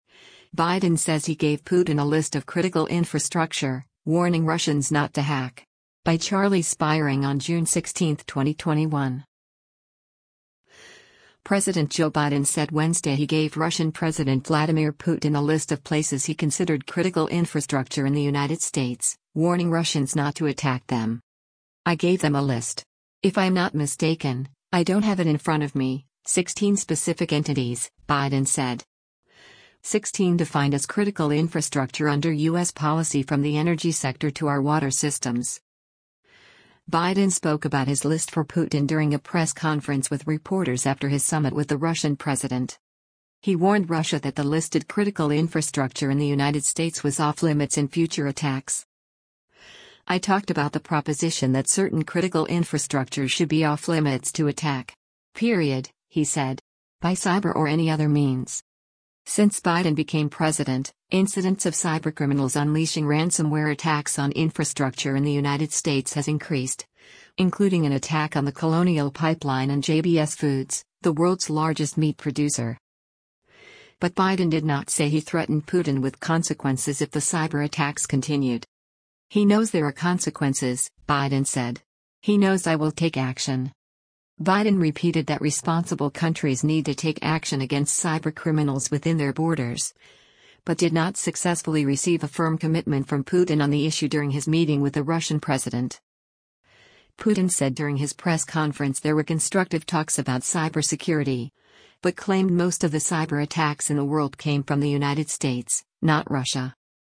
Biden spoke about his list for Putin during a press conference with reporters after his summit with the Russian president.